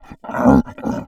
MONSTER_Effort_03_mono.wav